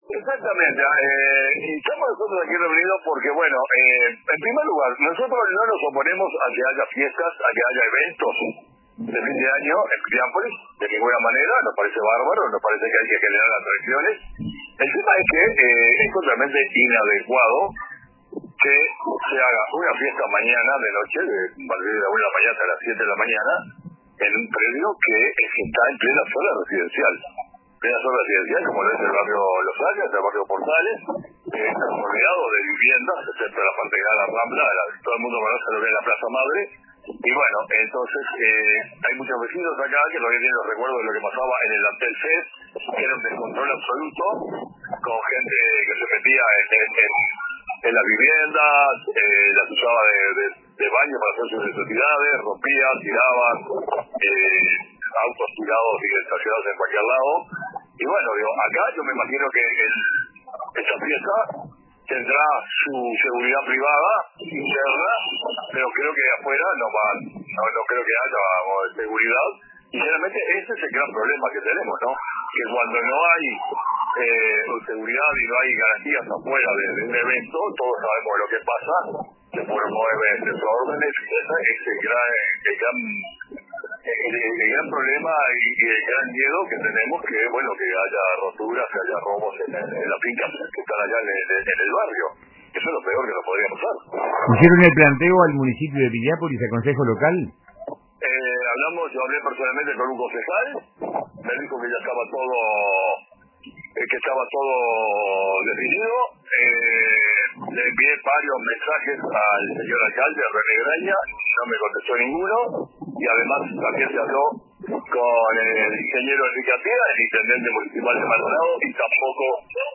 declaró al informativo central de RADIO RBC: